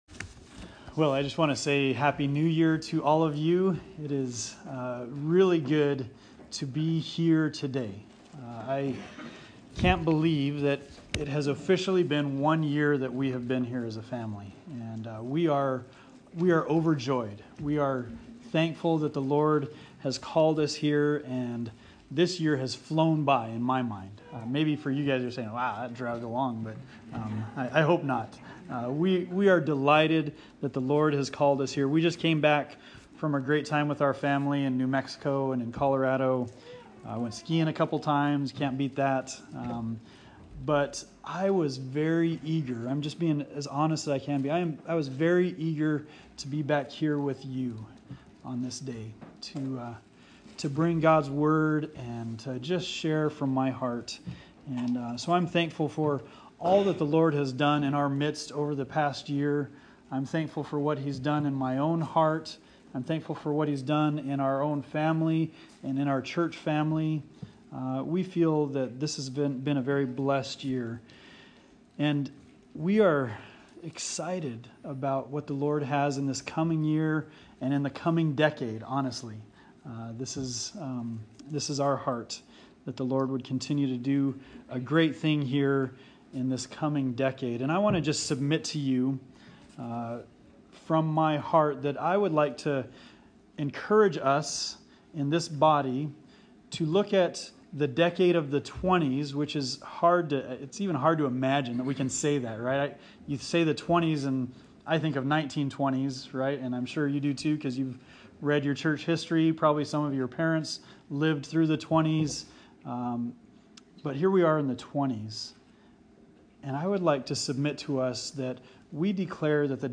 Bible Text: 1 Peter 3:8-9 | Preacher: